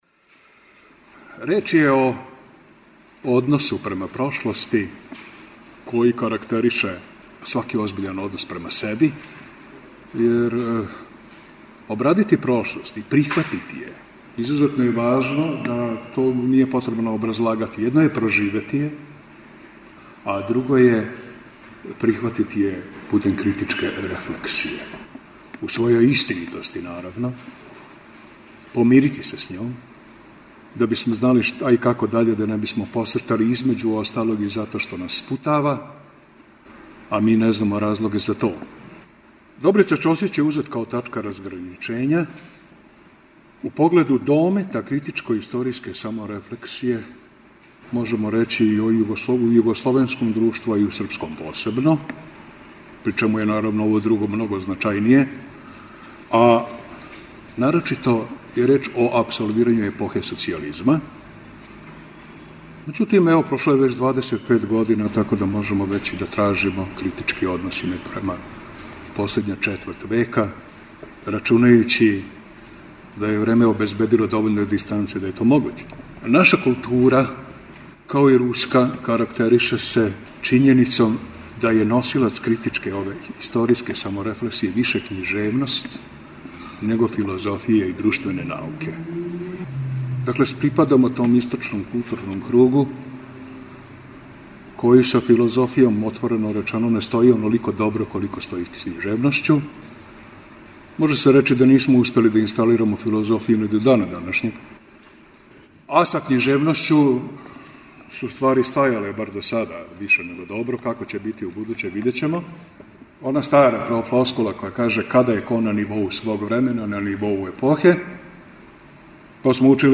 У циклусу НАУЧНИ СКУПОВИ четвртком емитујемо снимке са 27. Крушевачке филозофско-књижевне школе, чија тема је била 'Од корена до деоба – друштвена слика Србије у 20. веку'.
У оквиру Видовданских свечаности града Крушевца 17. и 18. јуна у Културном центру Крушевац одржана је 27. Крушевачка филозофско-књижевна школа.